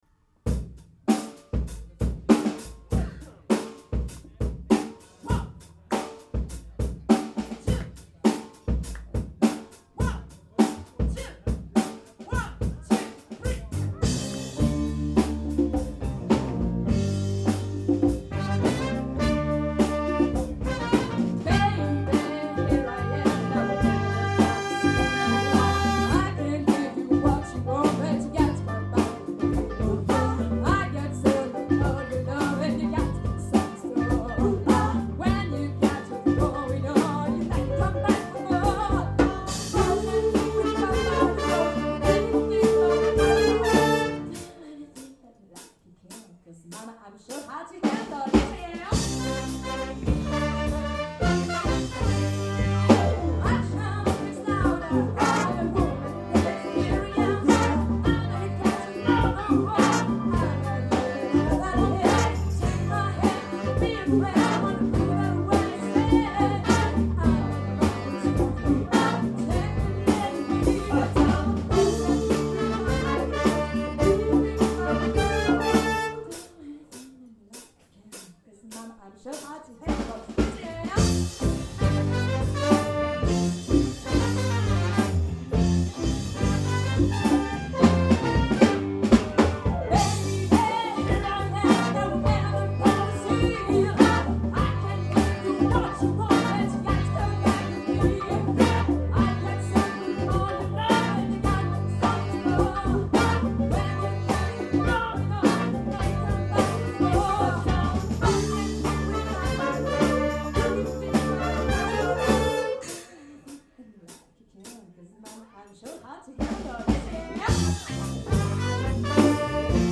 Big  Band - Rythm'n Blues